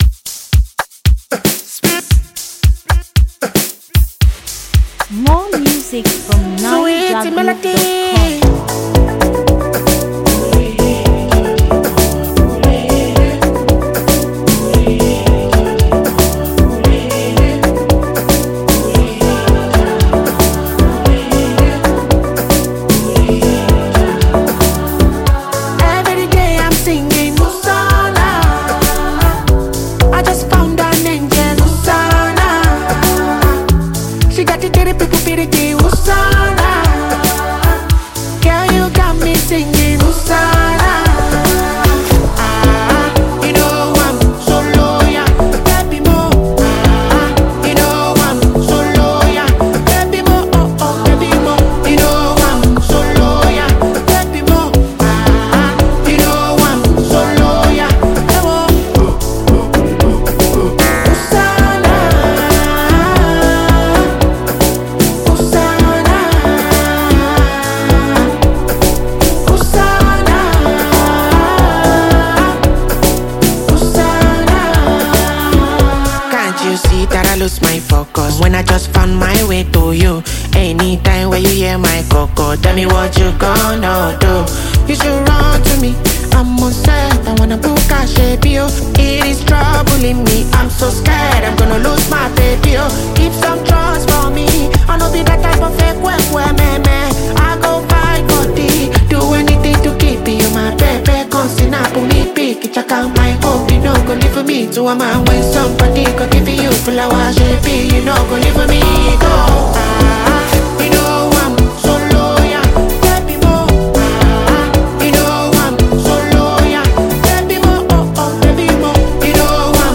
Naija-music
The highly talented afrobeat singer-songwriter and performer